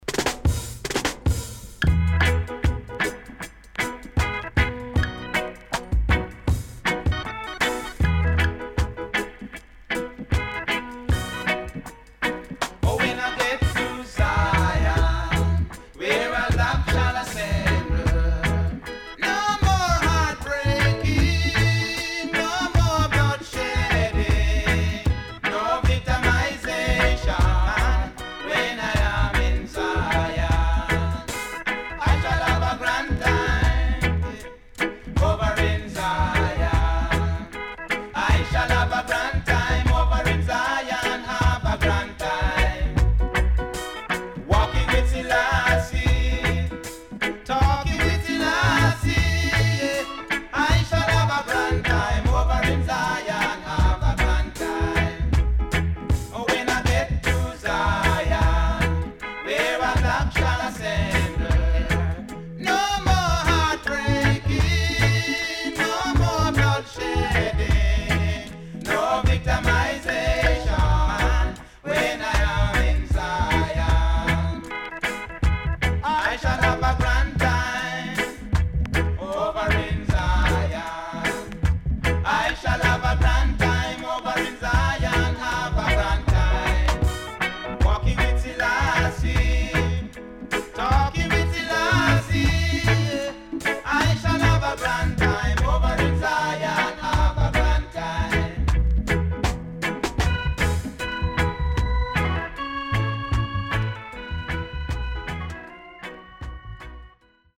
いなたいNice Roots Vocal